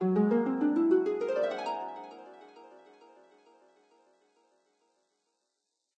magic_harp_1.ogg